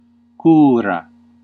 Ääntäminen
US : IPA : [kɛɹ] RP : IPA : /kɛə/ GenAm: IPA : /keəɻ/